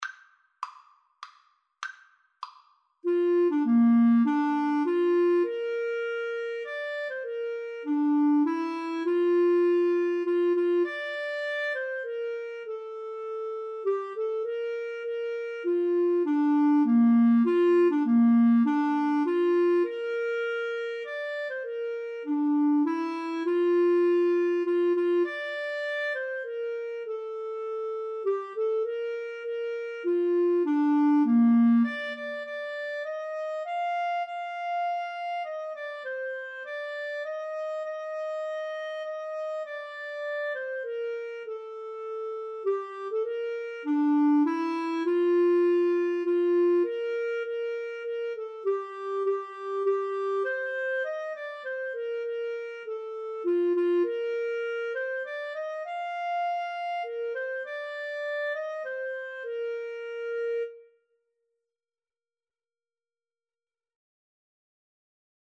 3/4 (View more 3/4 Music)
Bb major (Sounding Pitch) (View more Bb major Music for Clarinet-French Horn Duet )
Traditional (View more Traditional Clarinet-French Horn Duet Music)